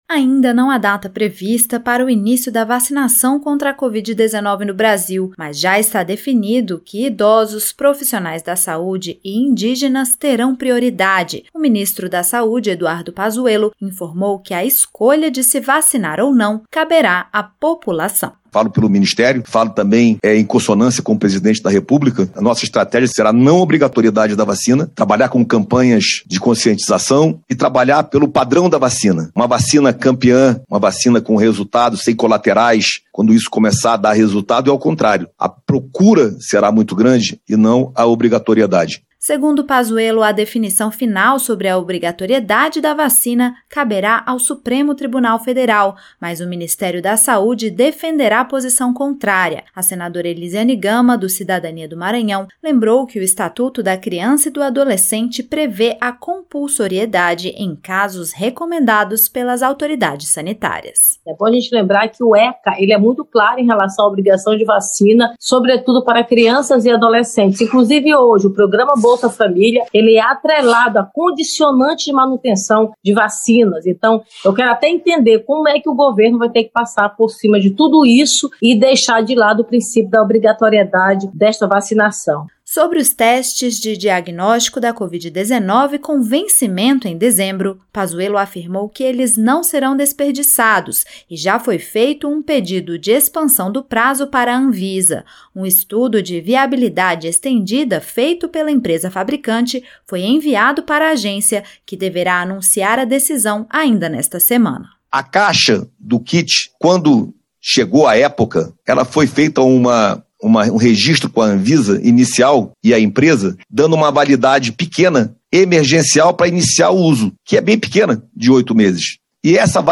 O ministro da Saúde, Eduardo Pazuello, afirmou nesta quarta-feira (2) que os testes de diagnóstico de covid-19 com vencimento e dezembro deverão ter o prazo estendido pela Anvisa. O ministro participou de uma audiência pública na comissão mista que acompanha as ações de enfrentamento ao coronavírus.